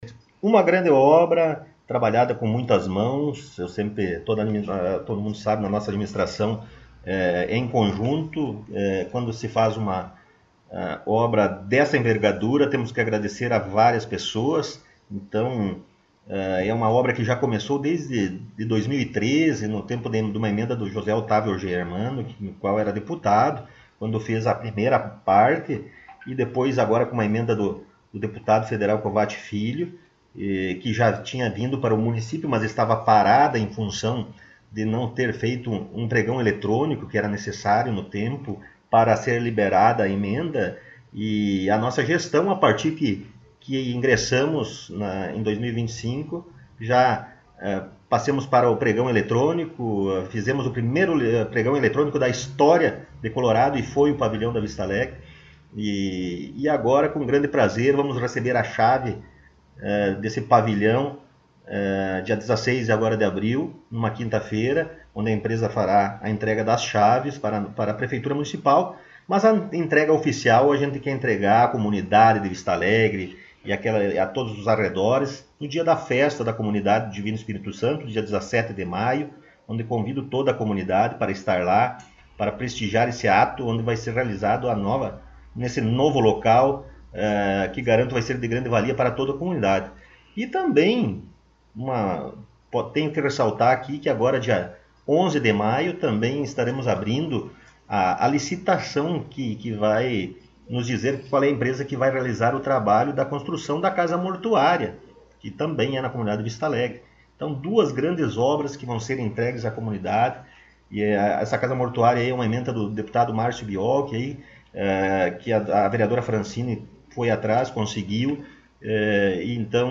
Prefeito Rodrigo Sartori concedeu entrevista